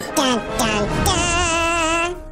meme sounds